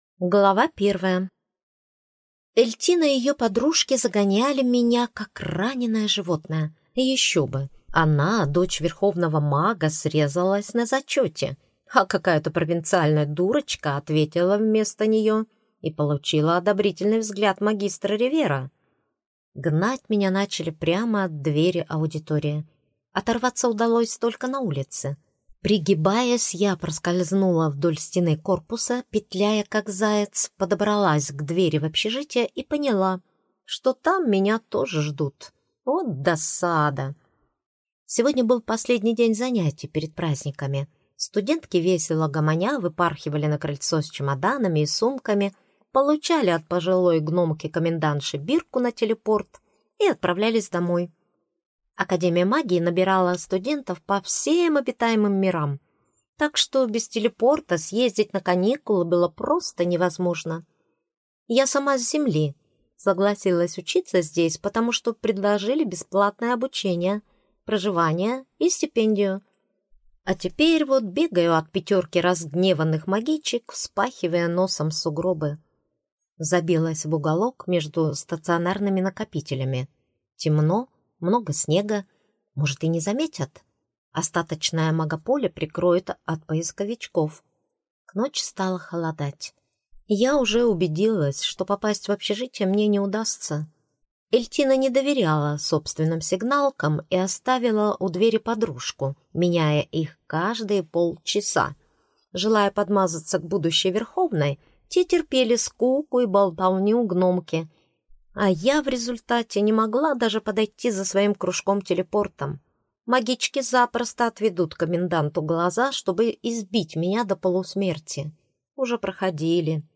Аудиокнига Подарок для инкуба | Библиотека аудиокниг